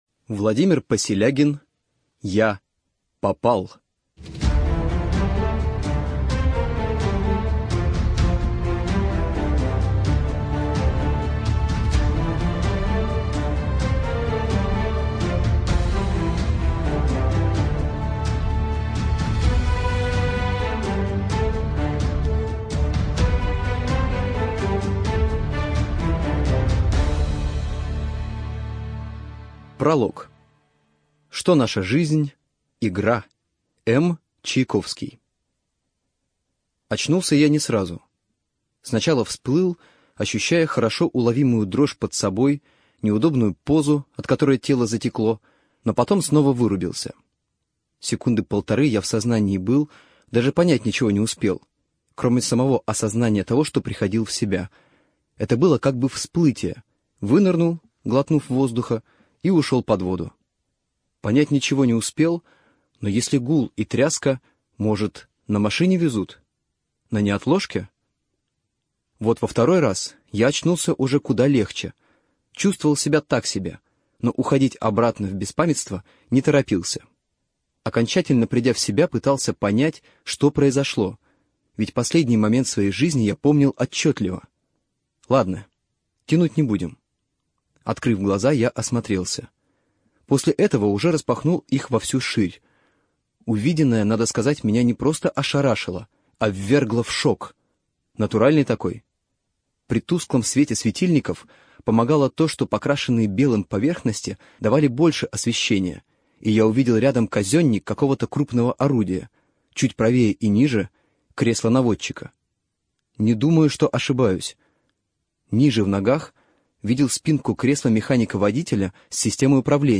ЖанрФантастика, Боевики